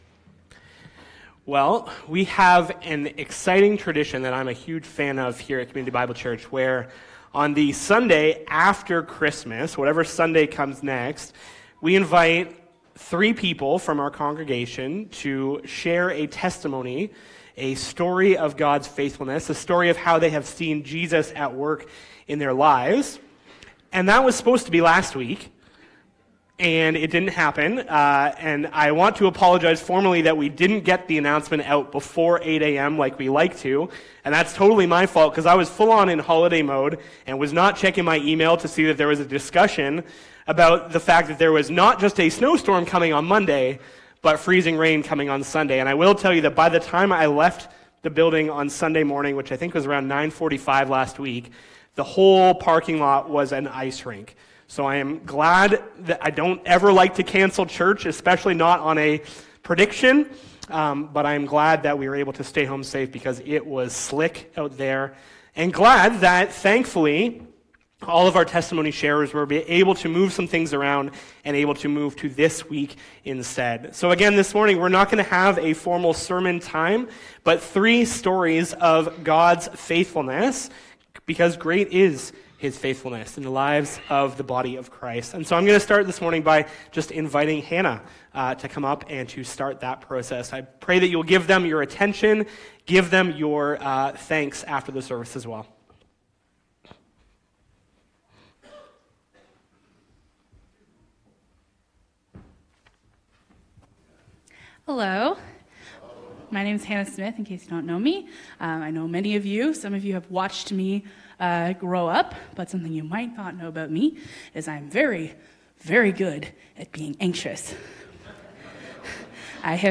Sermon Audio and Video Testimony Sunday!